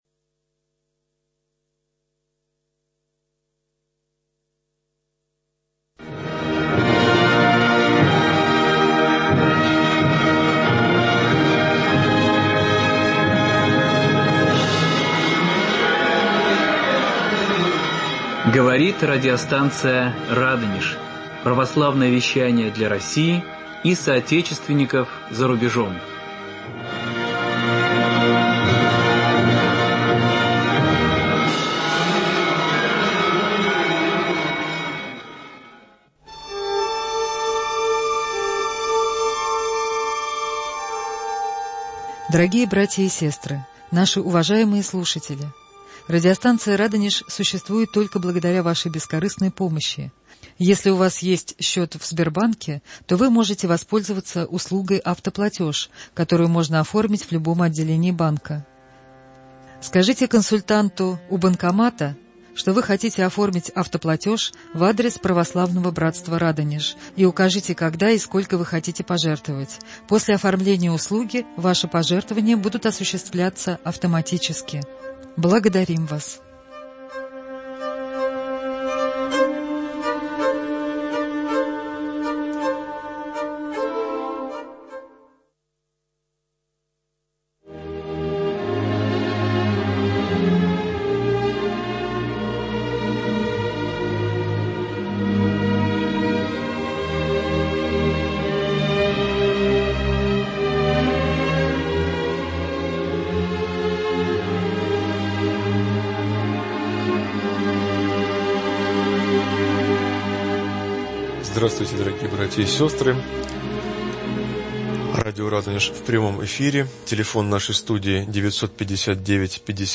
Прямой эфир.
Ответы на вопросы радиослушателей